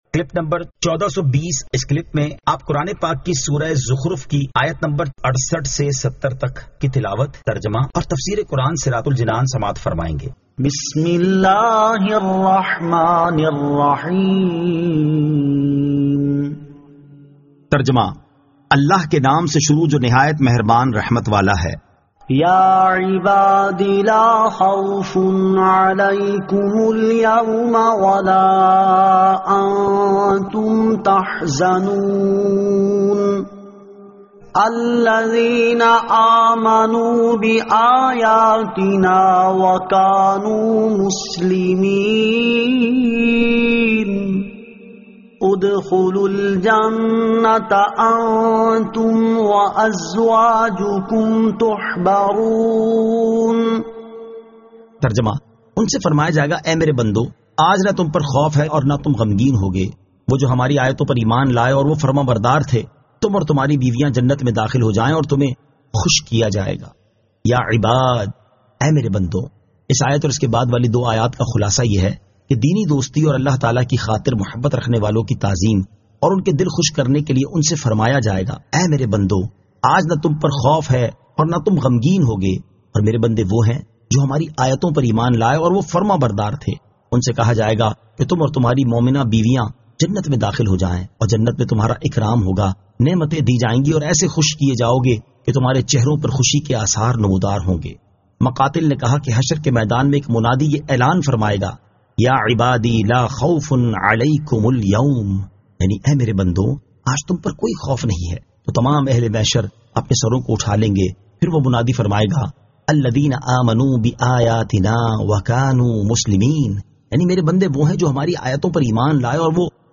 Surah Az-Zukhruf 68 To 70 Tilawat , Tarjama , Tafseer